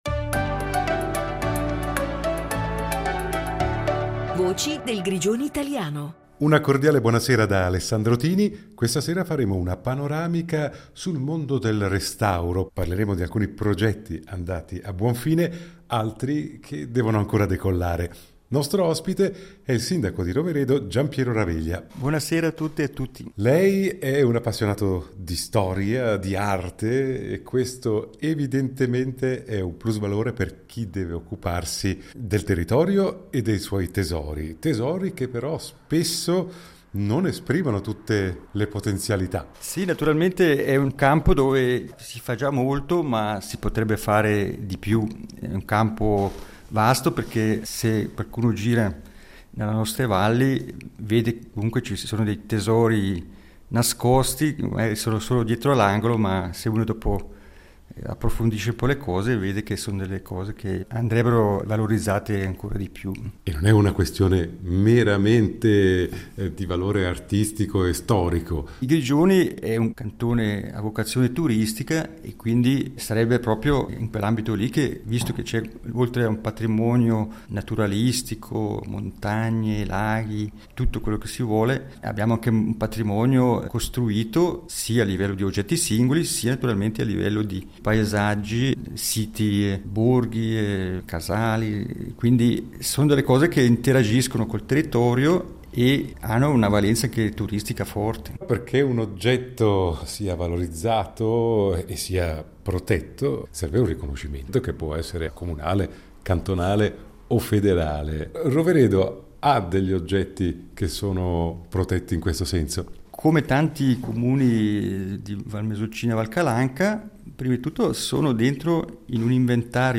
Voci del Grigioni italiano Il fascino del passato Progetti di restauro 29.09.2023 22 min Contenuto audio Disponibile su Scarica In questa edizione parliamo di restauri. Nostro ospite è il sindaco di Roveredo Giampiero Raveglia. Analizzeremo alcuni progetti andati a buon fine, altri che devono ancora decollare.